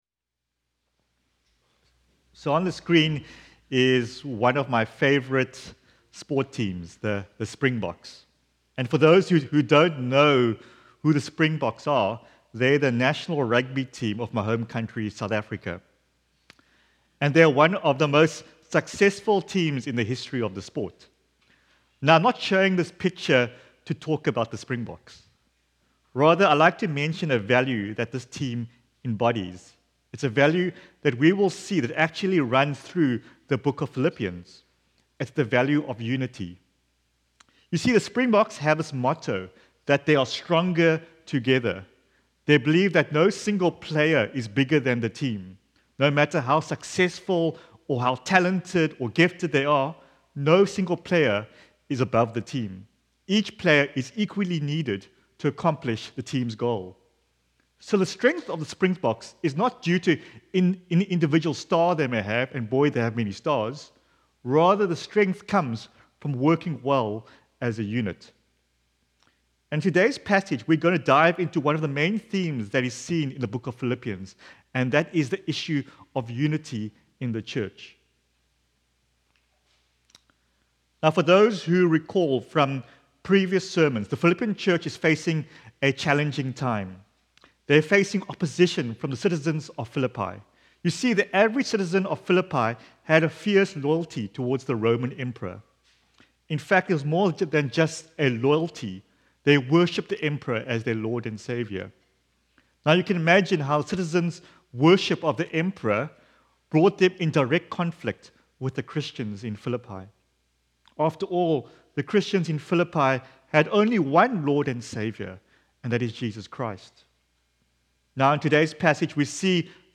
Sermons - Jacqueline Street Alliance Church